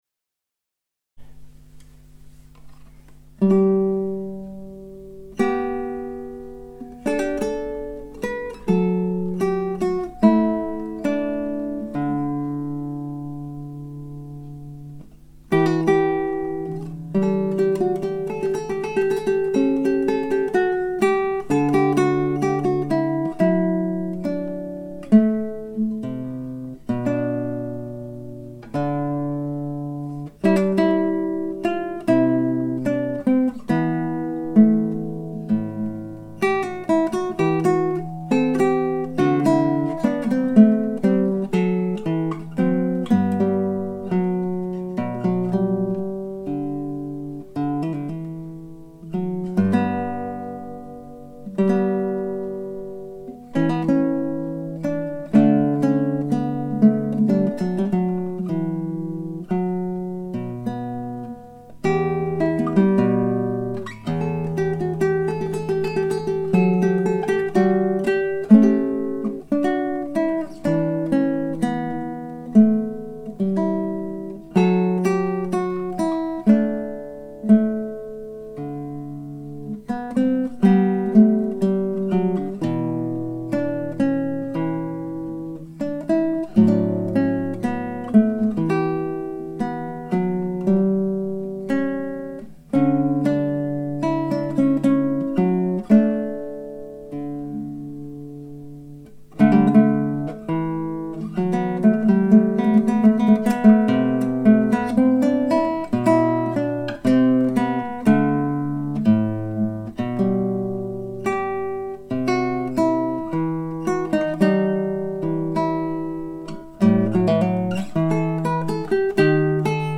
One performance, two microphones.
The one attached (original was 16bit .wav) was made with a BM-800 LDC (sic) the REALLY cheap jobby.
I am sure you folks can hear the hum click in at the start and then can be heard at the last gasp the clicks off? I have isolated the hum and attached a spectrum. some 50Hz and a bit more at 150Hz. That indicates inductive pickup of a transformer, if it were 'system' noise it would be at 100Hz.
*He apologizes for the poor playing. The exercise was for mic testing and he has only just begun to learn that Goldberg variation.